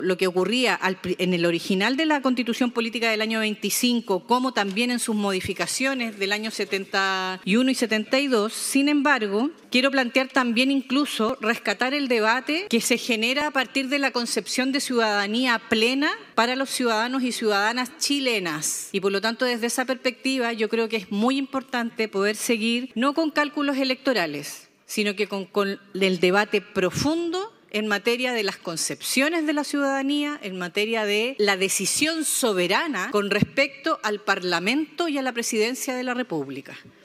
En tanto, la senadora Claudia Pascual destacó que el proyecto busca recuperar coherencia en la tradición constitucional chilena respecto a la ciudadanía y el voto.